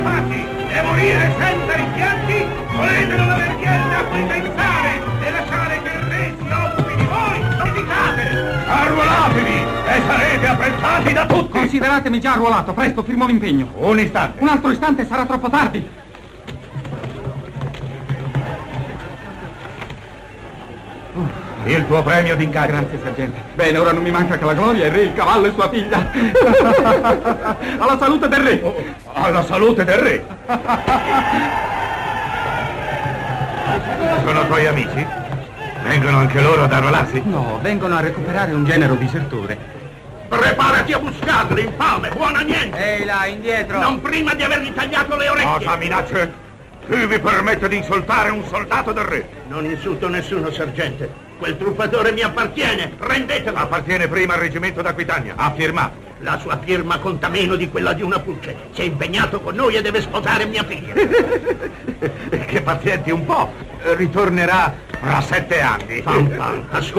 nel film "Fanfan le Tulipe", in cui doppia Nerio Bernardi.